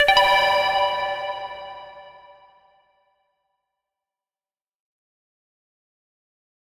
menu-freeplay-click.ogg